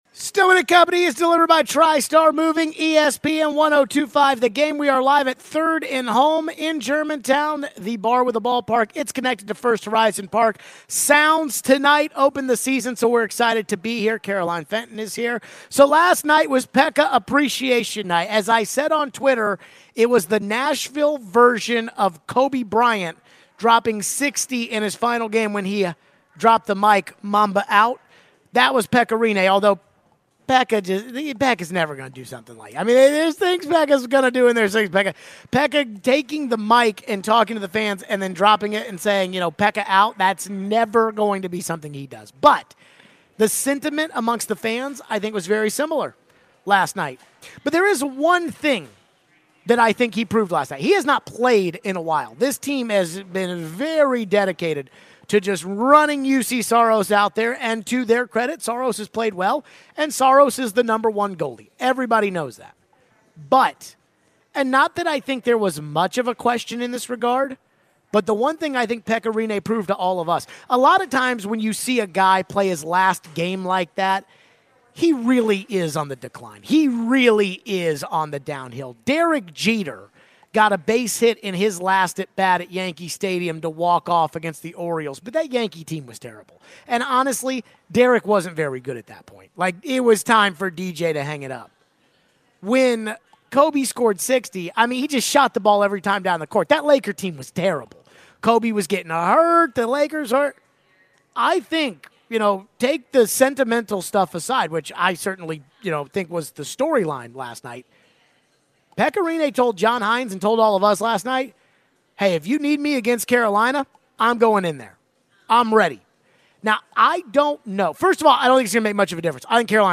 We take your phone calls.